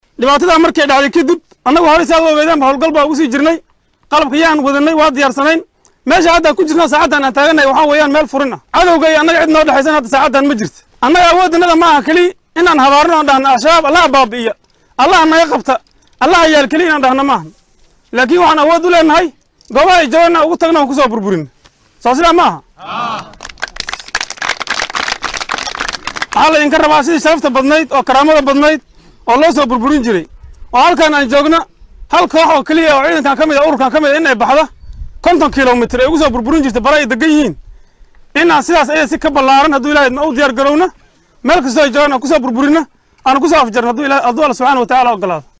Dhageyso Abaanduulaha guutada 9-aad ee Ciidamada xooga dalka oo gobolka Gado kaga dhawaaqay dagaal